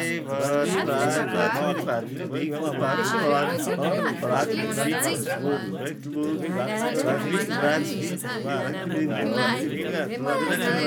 Humam Mumble [loop] (1).wav